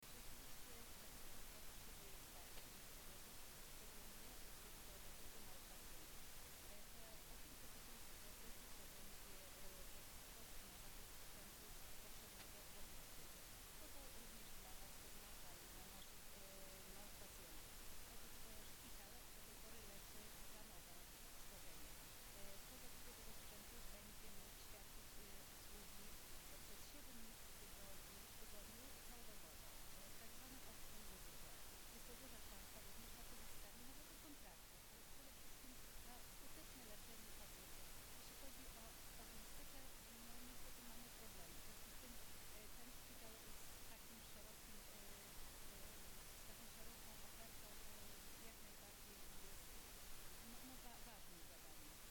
Wypowiedź p. Elżbiety Lanc, członka zarządu województwa mazowieckiego,